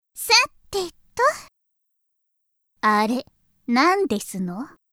１０代半ばぐらいの少女。
お嬢様言葉を使うがたまに変な言葉使いになる。
ボイスサンプル@　　ボイスサンプルA